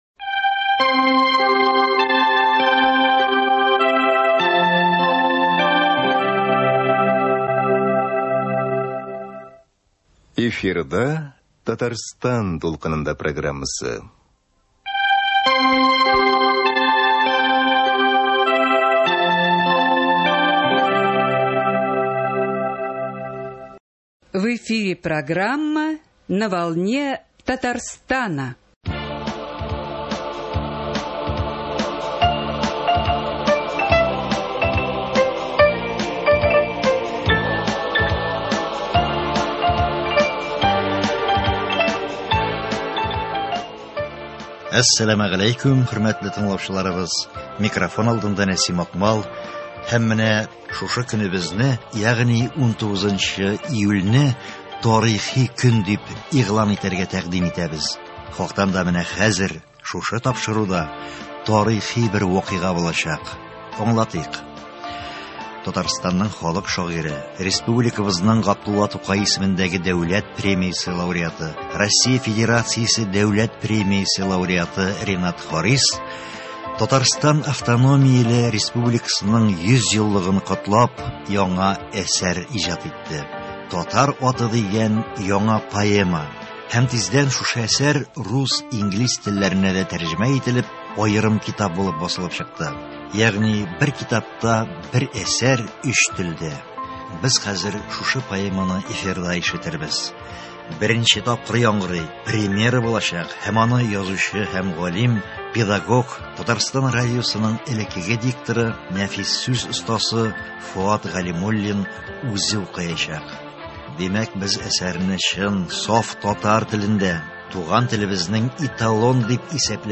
Һәм, хөрмәтле тыңлаучылар, шушы поэманы без хәзер эфирда ишетербез.
Димәк, без әсәрне чын, саф татар телендә, туган телебезнең эталон дип исәпләнерлек бер югарылыгында ишетәчәкбез.